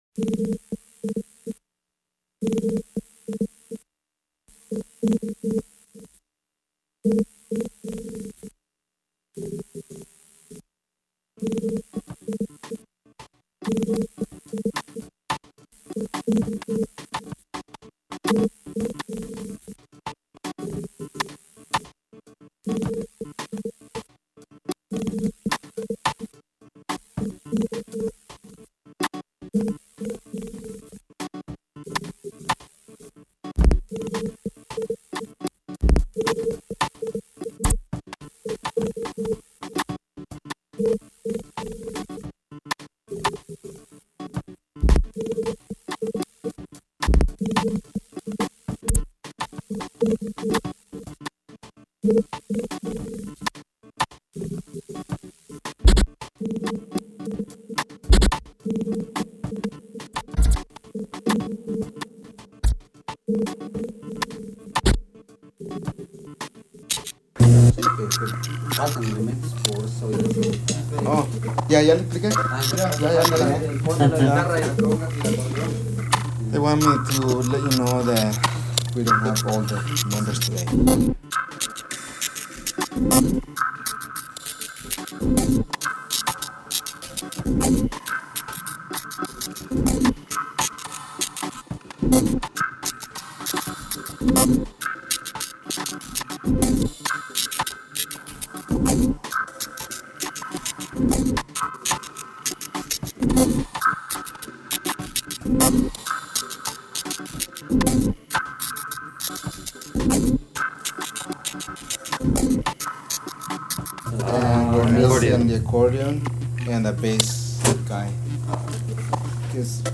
minimalismo
electronic norteña